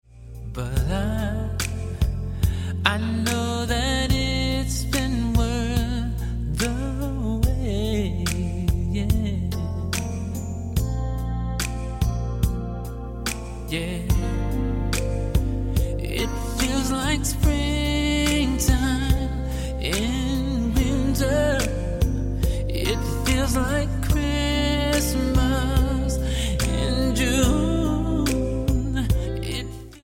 Genre: Jazz
Saxophone